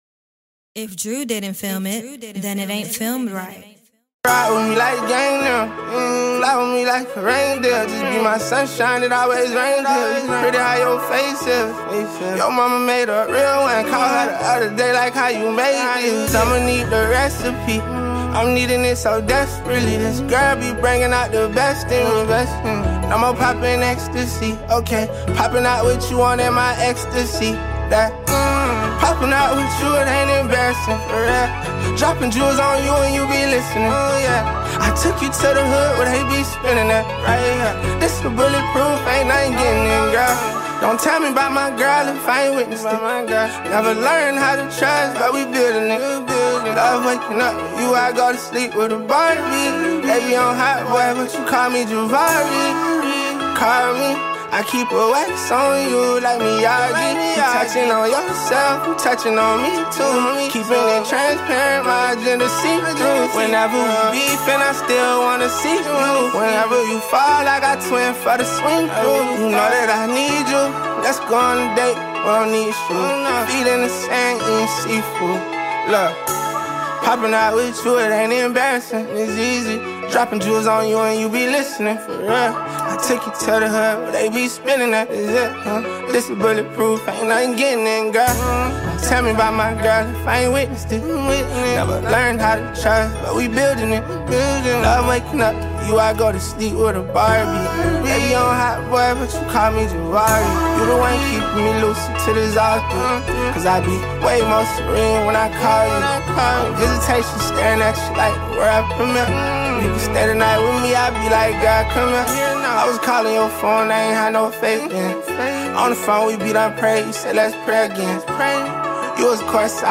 blending rhythm, soul, storytelling, and modern sound